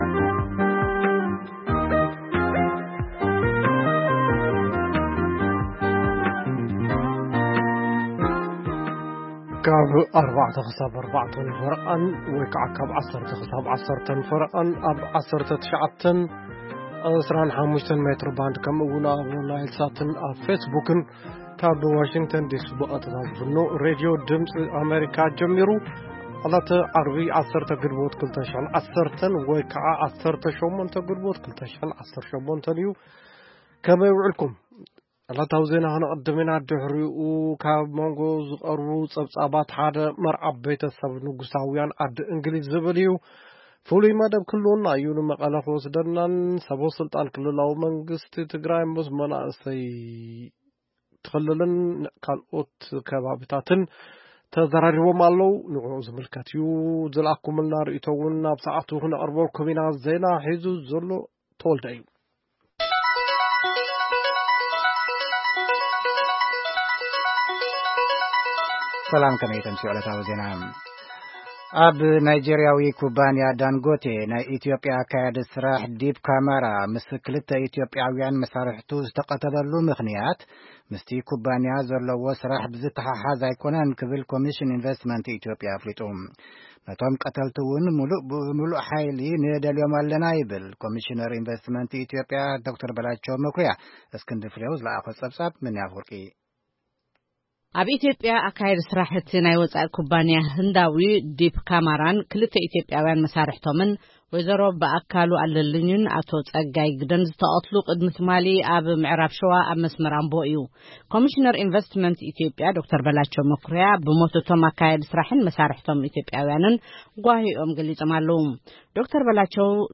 ፈነወ ትግርኛ ብናይ`ዚ መዓልቲ ዓበይቲ ዜና ይጅምር ። ካብ ኤርትራን ኢትዮጵያን ዝረኽቦም ቃለ-መጠይቓትን ሰሙናዊ መደባትን ድማ የስዕብ ። ሰሙናዊ መደባት ዓርቢ፡ ቂሔ-ጽልሚ / ፍሉይ መደብ/ ሕቶን መልስን